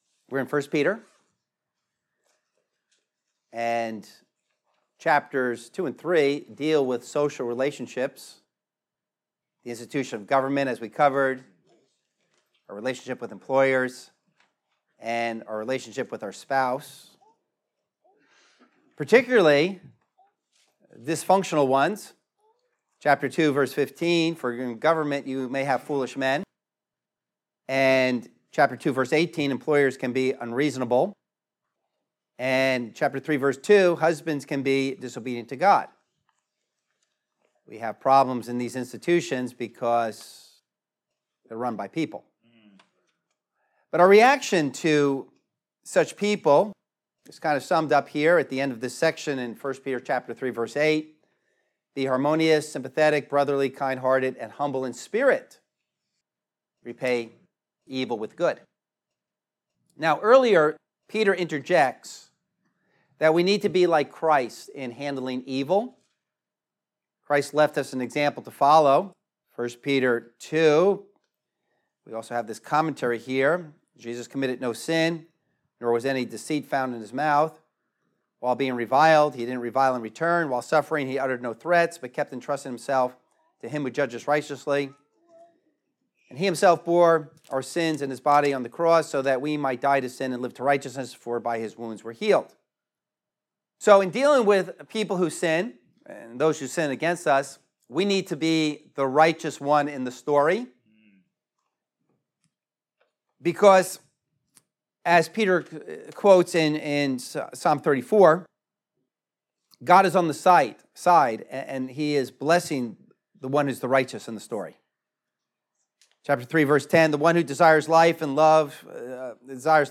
1 Peter Series #16 - 3:8-22 - What to do when in Trouble | Message